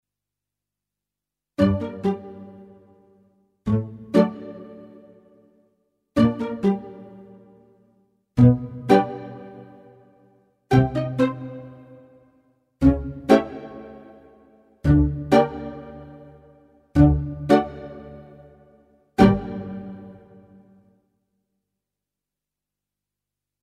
suspense - humouristique - etrange - pizzicato| magique